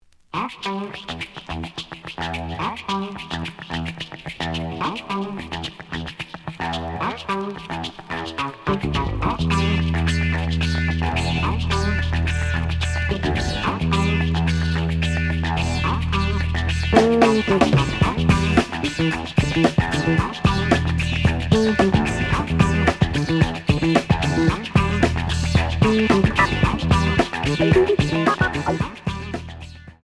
The audio sample is recorded from the actual item.
●Format: 7 inch
●Genre: Jazz Funk / Soul Jazz